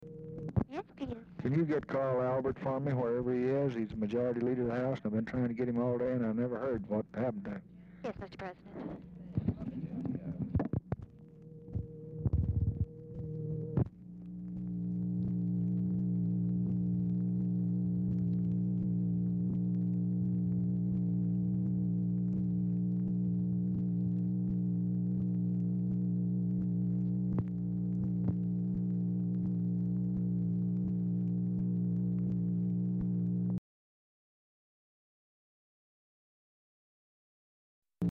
Telephone conversation # 4237, sound recording, LBJ and TELEPHONE OPERATOR, 7/14/1964, time unknown | Discover LBJ
Format Dictation belt
Location Of Speaker 1 Oval Office or unknown location